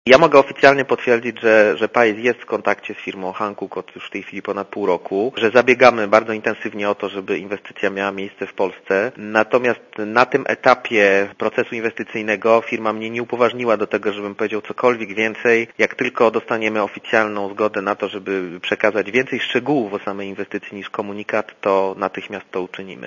Posłuchaj komentarza Sebastiana Mikosza